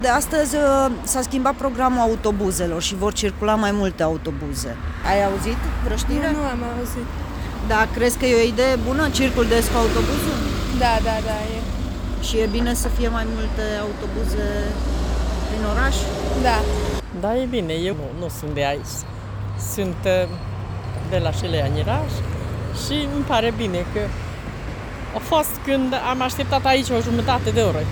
Nu toţi călătorii ştiu încă de modificări, dar consideră măsura bine venită: